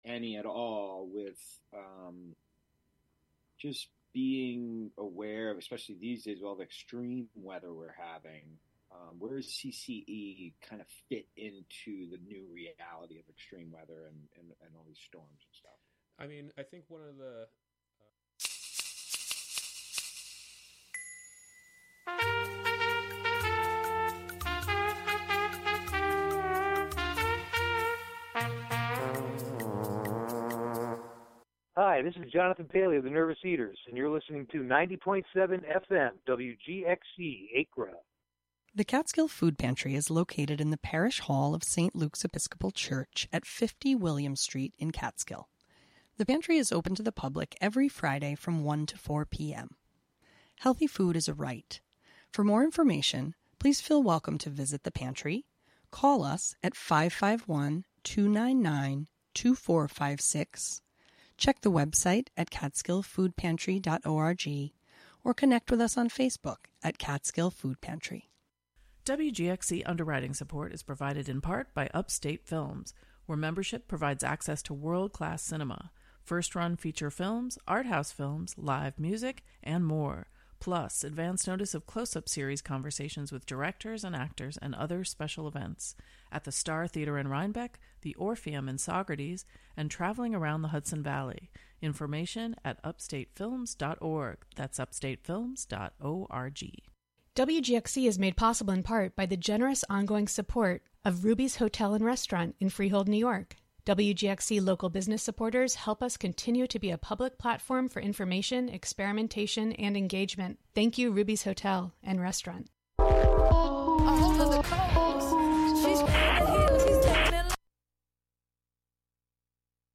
Listen to us chat live in the studio as they highlight their top new songs from 2023.
The show features music, field recordings, performances, and interviews, primarily with people in and around the Catskill Mountains of New York live from WGXC's Acra studio.